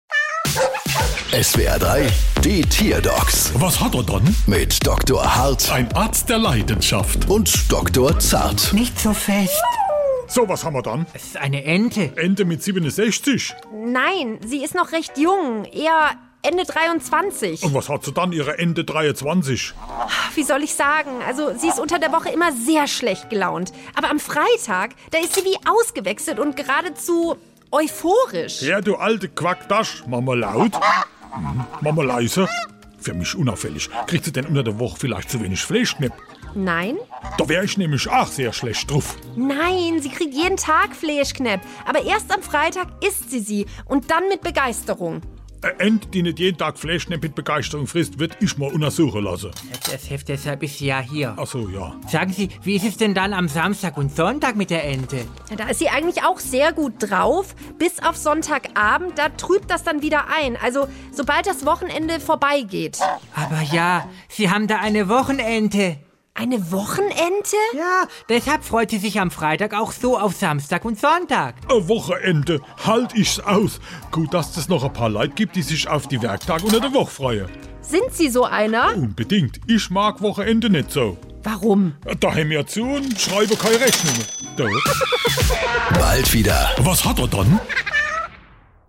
Stand-up Comedy